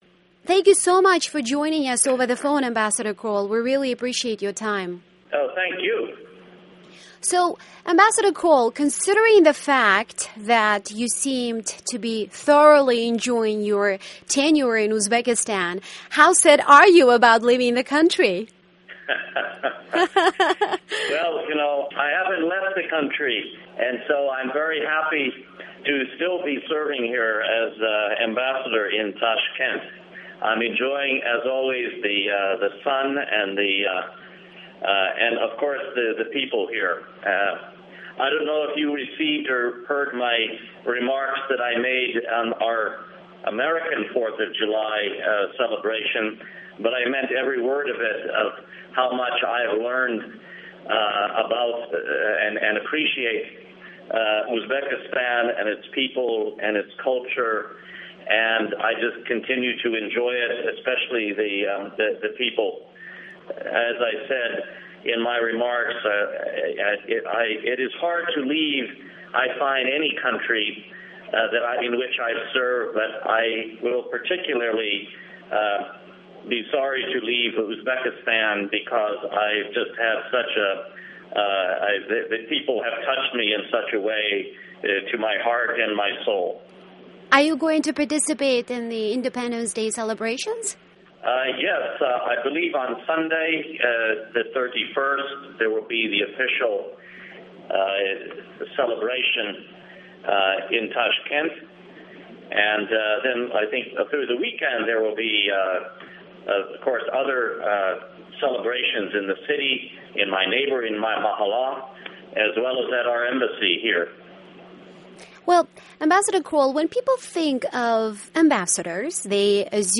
Exclusive interview
US-Uzbek relations, the next post in Kazakhstan, role & power of an ambassador, dialog & progress, upcoming parliamentary & presidential elections in Uzbekistan... George Krol, US Ambassador to Uzbekistan in an interview